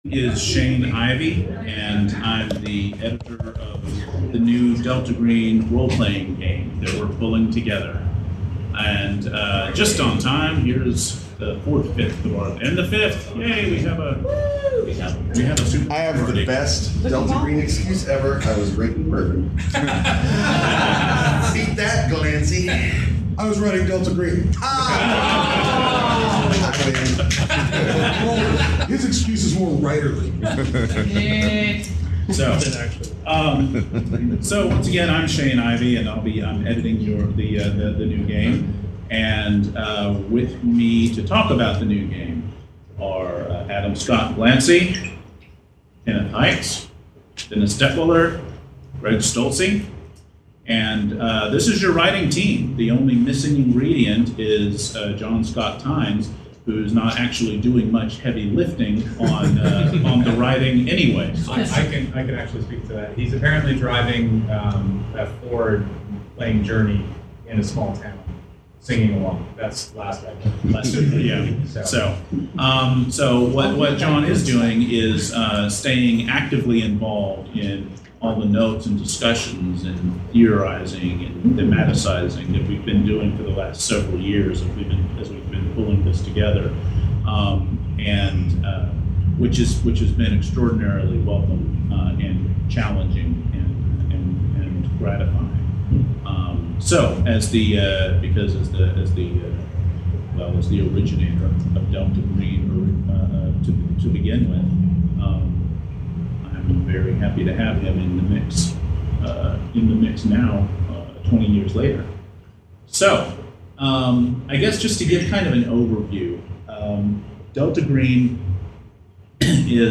(Apologies for the sporadic audio quality. Due to the configuration of the room we had to place the microphone farther from the speakers than we would have liked.)